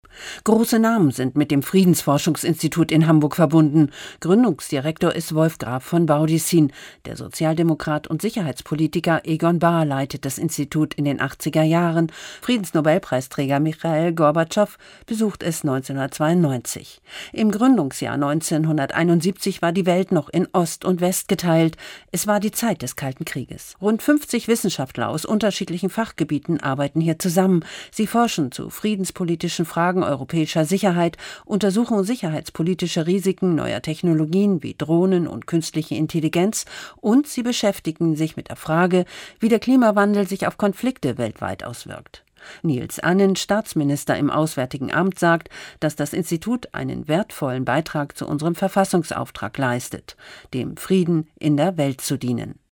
Nachrichtenbeitrag in NDR 90,3 Aktuell am 11.06.2021